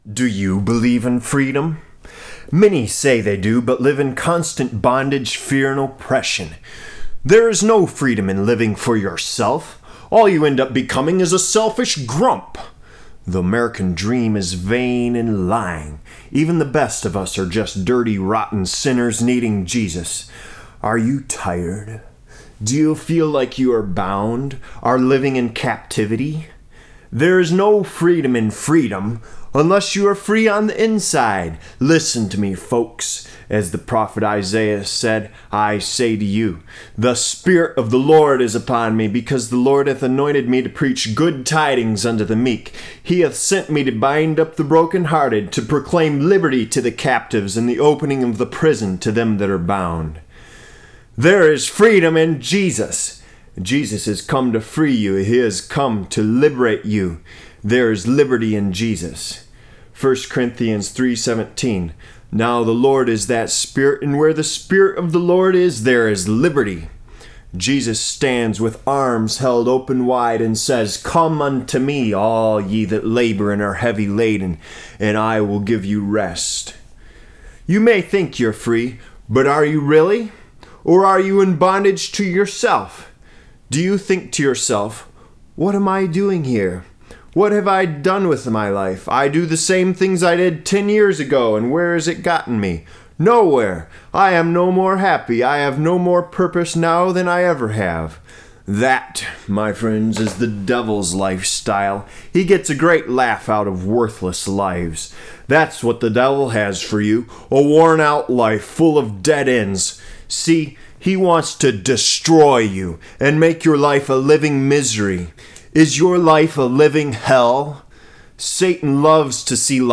Freedom by Jesus Hear this spoken here.